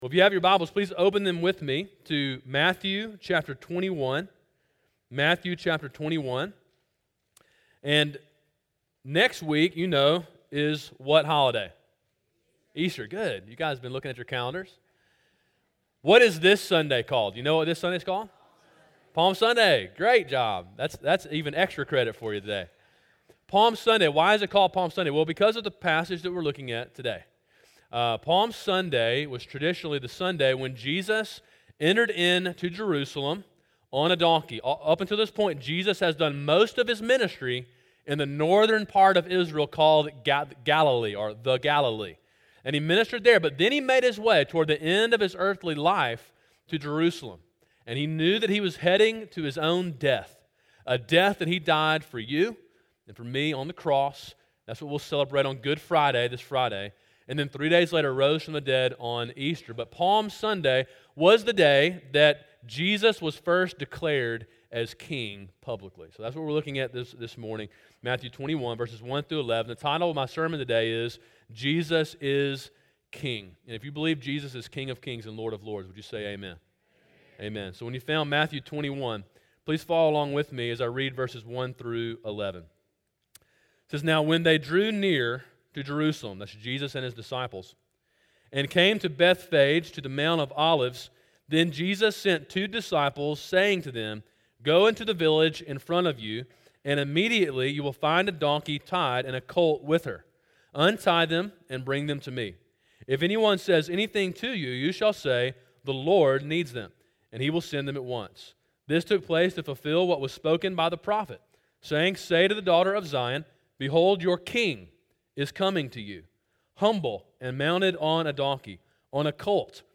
Sermon: “Jesus Is King” (Matthew 21:1-11)
sermon3-25-18.mp3